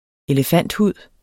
elefanthud substantiv, fælleskøn Bøjning -en Udtale Betydninger 1. elefantens tykke hud En mand, der udbyder punge af elefanthud, lover, at han sagtens kan skaffe flere fra Thailand.